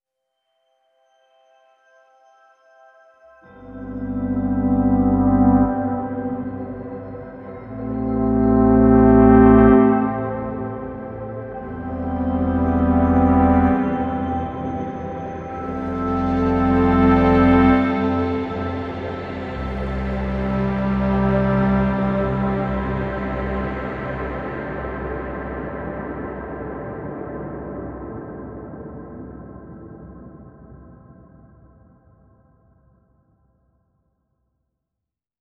• Жанр: Электро